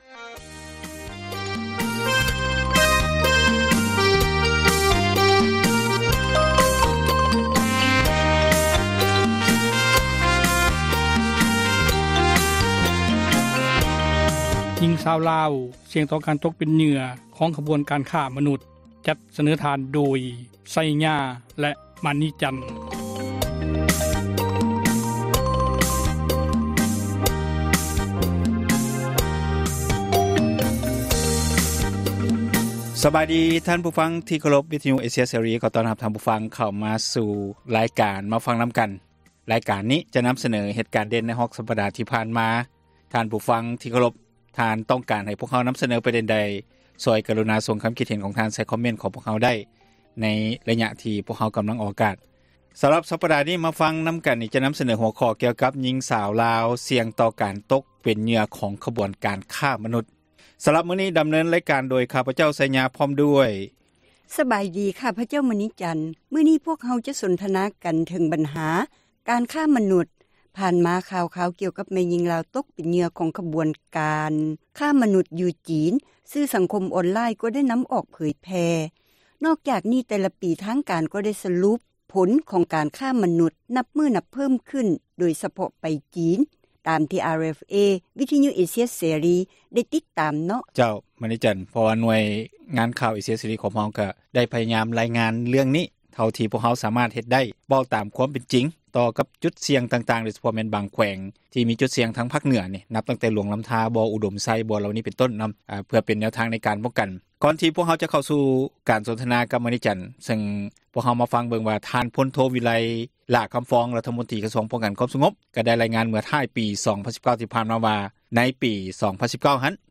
"ມາຟັງນຳກັນ" ແມ່ນຣາຍການສົນທະນາ ບັນຫາສັງຄົມ ທີ່ຕ້ອງການ ພາກສ່ວນກ່ຽວຂ້ອງ ເອົາໃຈໃສ່ແກ້ໄຂ, ອອກອາກາດ ທຸກໆວັນອາທິດ ເວລາ 6:00 ແລງ ແລະ ເຊົ້າວັນຈັນ ເວລາ 7:00